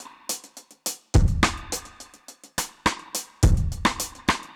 Index of /musicradar/dub-drums-samples/105bpm
Db_DrumsB_Wet_105-01.wav